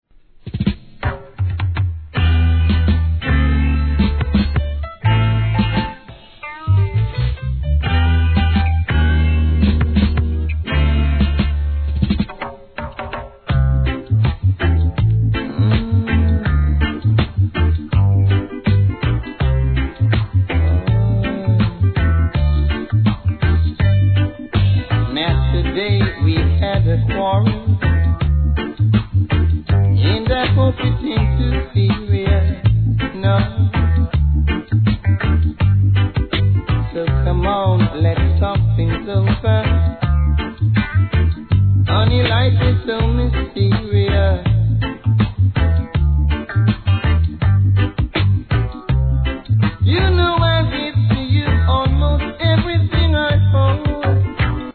REGGAE
あげないもんね!と余裕を感じさせる歌いっぷり♪ No. タイトル アーティスト 試聴 1.